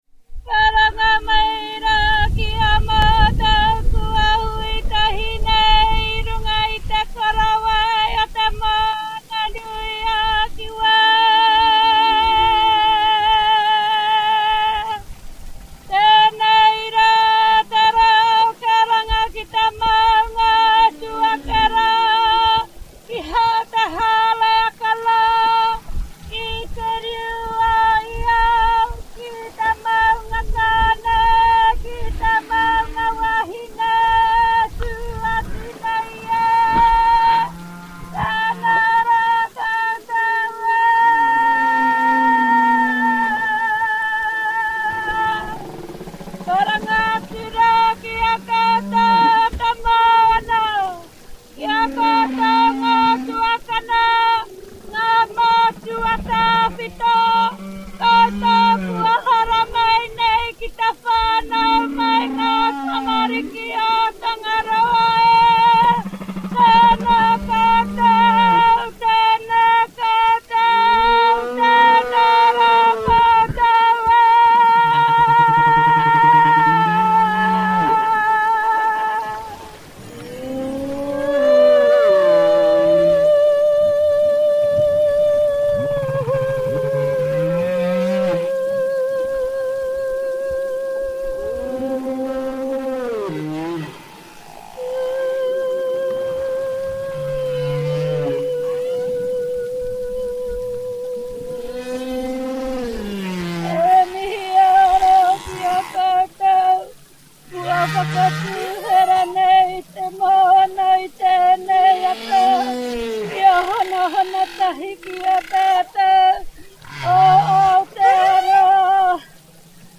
Maori-_Chant.mp3